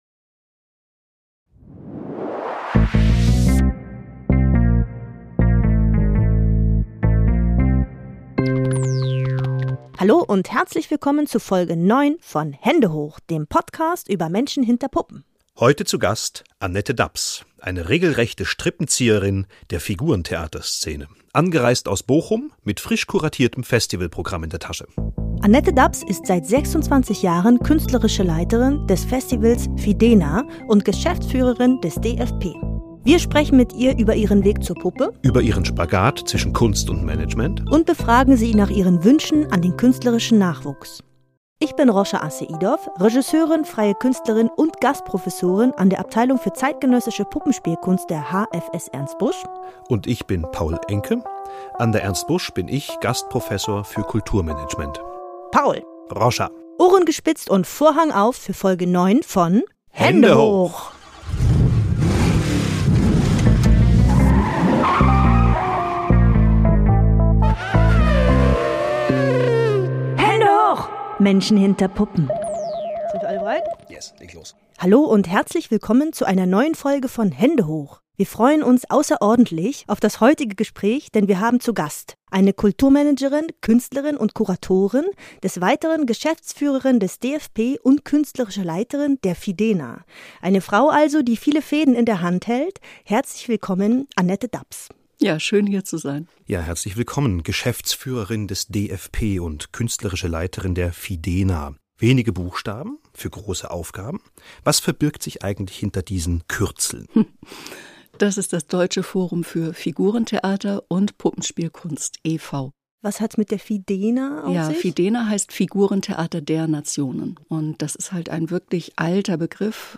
Die beiden Gastgebenden kommen mit ihr ins Gespräch über die Arbeit der FIDENA, über die Stärken und Schwächen von Stadttheater und Freier Szene und über mögliche Scheuklappen des Theaters.